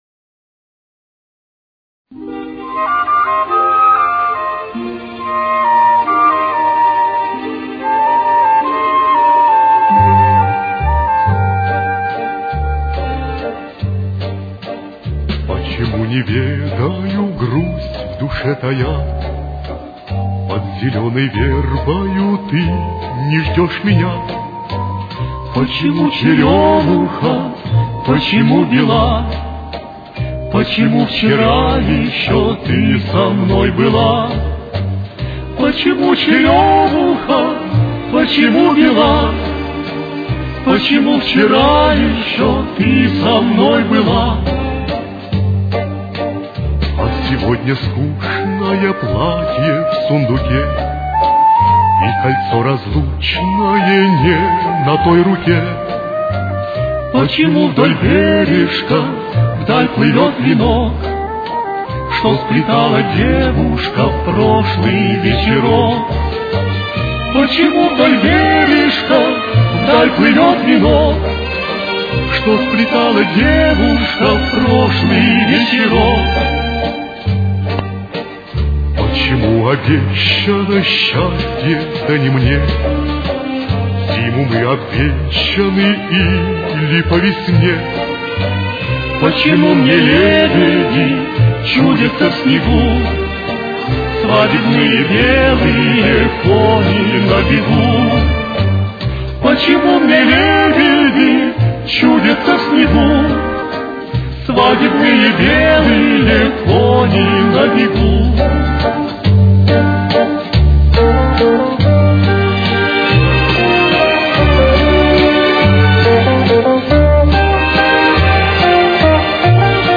Темп: 146.